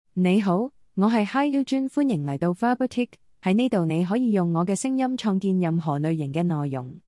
Hiujin — Female Cantonese AI voice
Hiujin is a female AI voice for Cantonese.
Voice sample
Listen to Hiujin's female Cantonese voice.
Hiujin delivers clear pronunciation with authentic Cantonese intonation, making your content sound professionally produced.